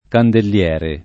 candelliere [ kandell L$ re ]